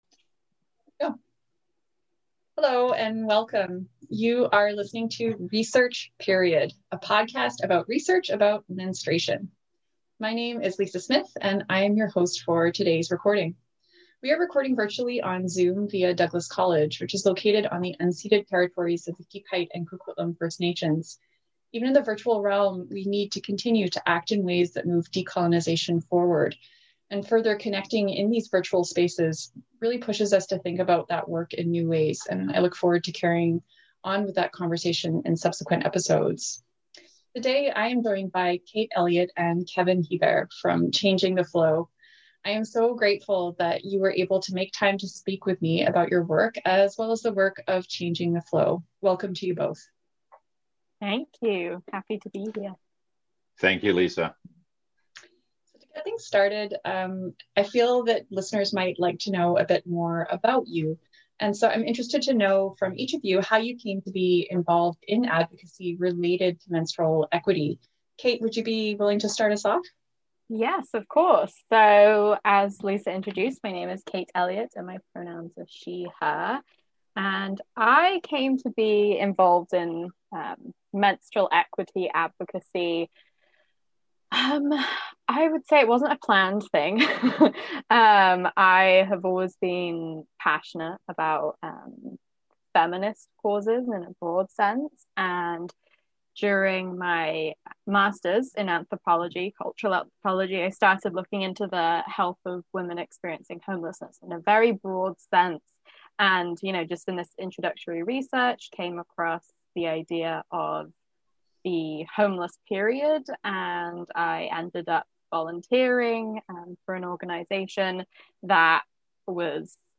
Research. Period. (Episode 4): A conversation